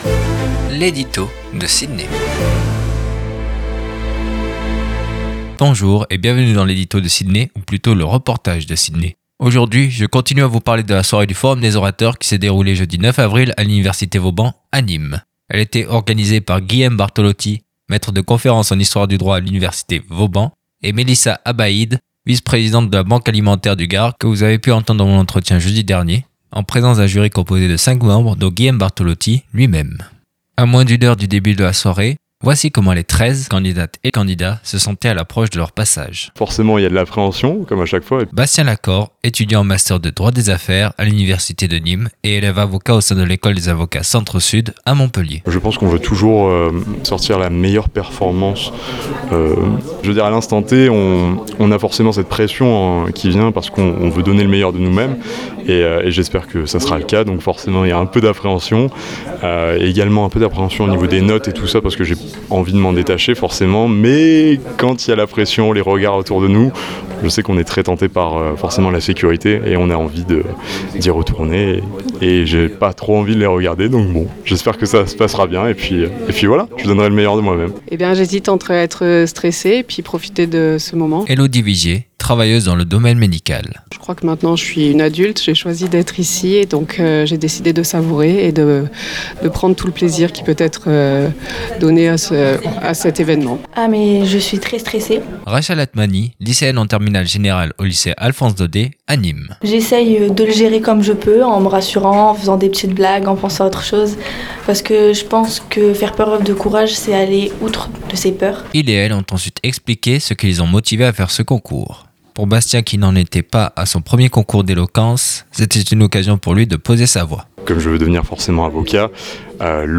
4. REPORTAGE SUR LA SOIRÉE DU FORUM DES ORATEURS À NÎMES